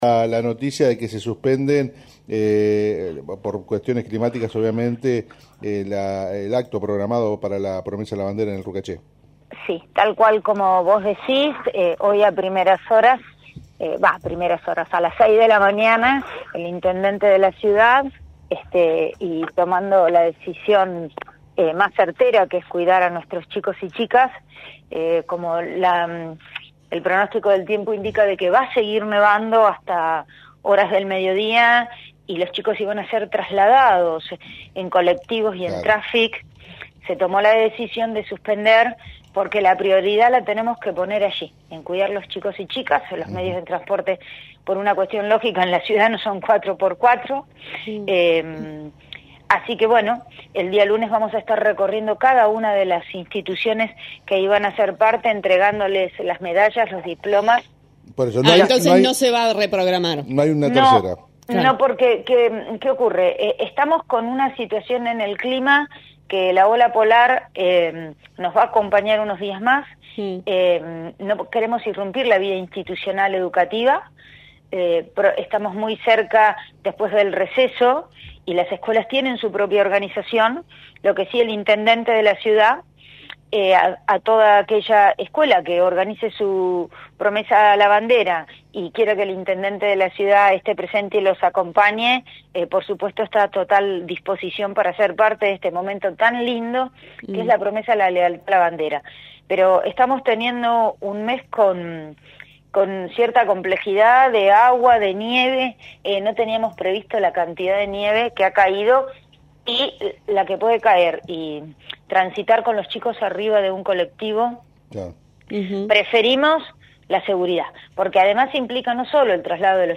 «El lunes estaremos recorriendo las escuelas y entregando los diplomas y medallas. No vamos a reprogramar porque las inclemencias climáticas se mantendrán durante los próximos días», afirmó Maria Pasqualini, secretaria de Capacitación de la municipalidad, en declaraciones a «Mañanas en Red».